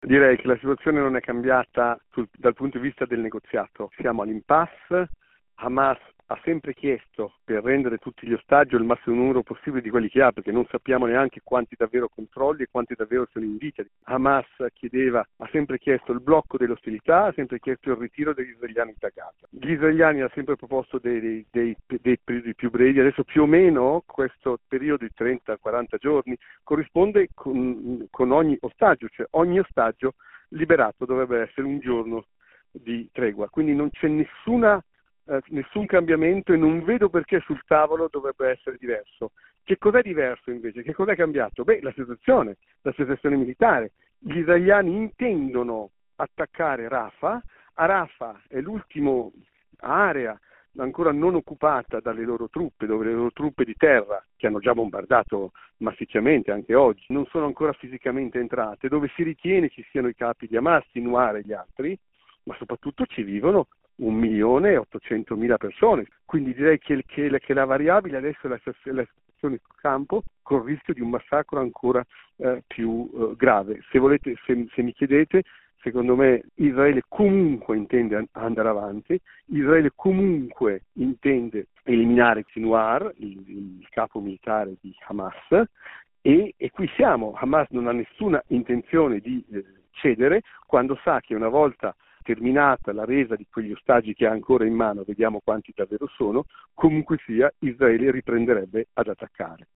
Il racconto della giornata di lunedì 29 aprile 2024 con le notizie principali del giornale radio delle 19.30. L’attesa per la risposta di Hamas alla proposta di tregua presentata da Israele.